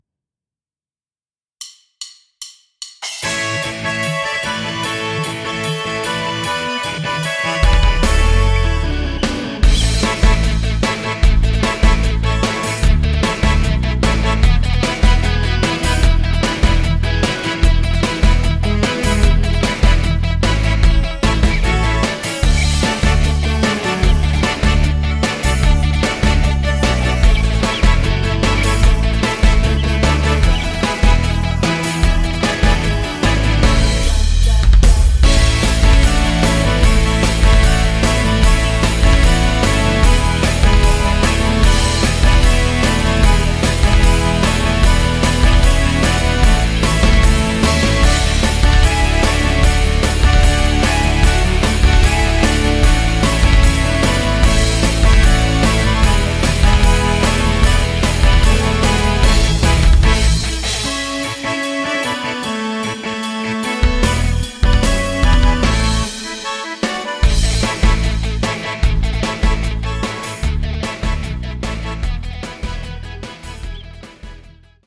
Playback, Instrumental, Karaoke